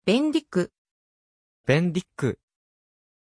Prononciation de Bendik
pronunciation-bendik-ja.mp3